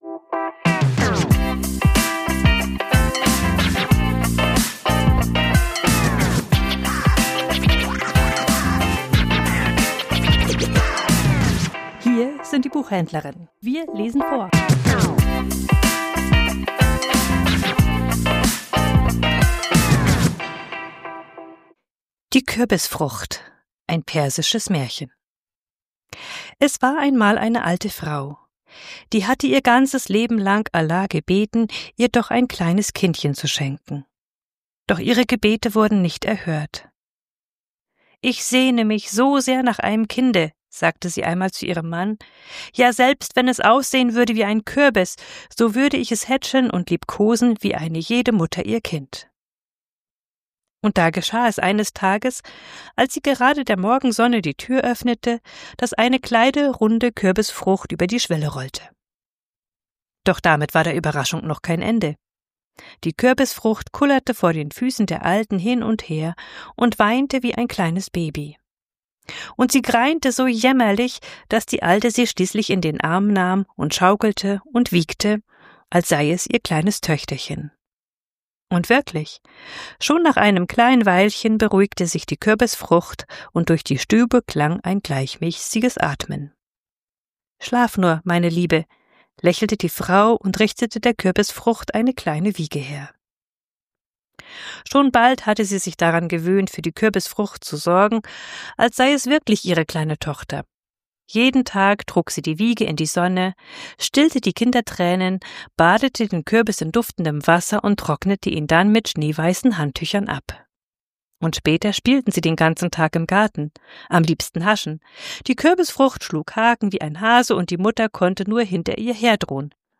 Vorgelesen: Die Kürbisfrucht ~ Die Buchhändlerinnen Podcast